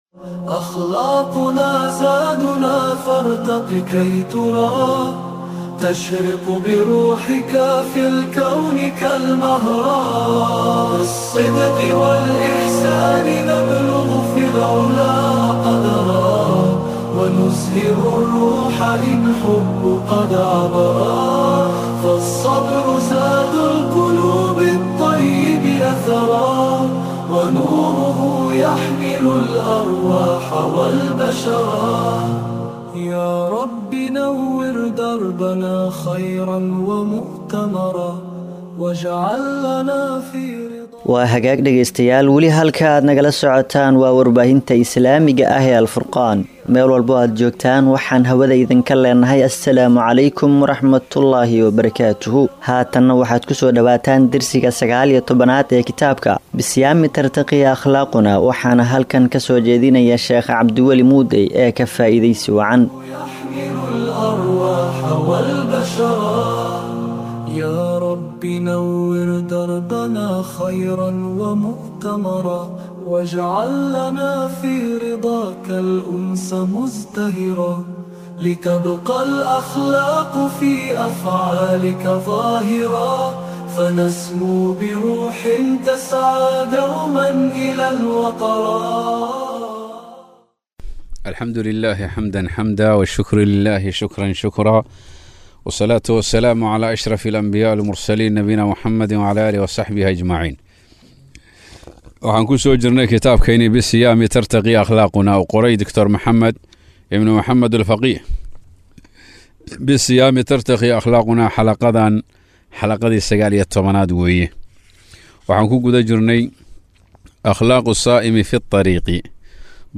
Dersiga 19aad